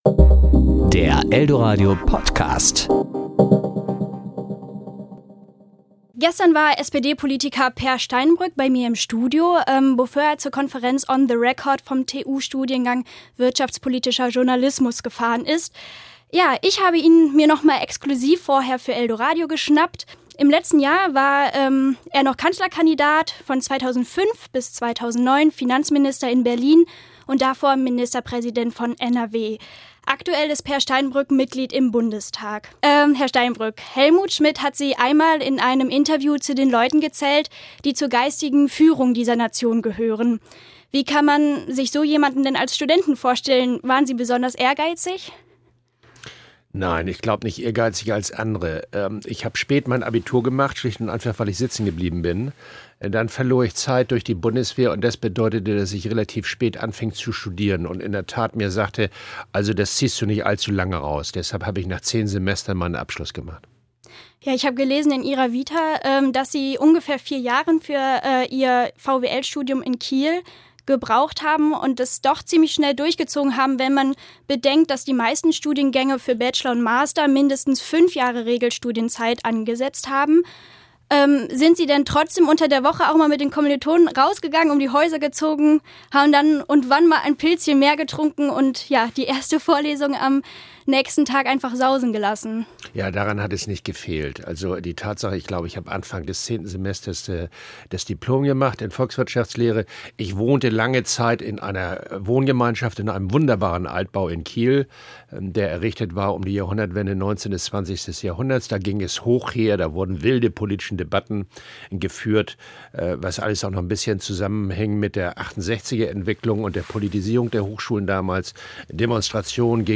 Interview Sendung: Toaster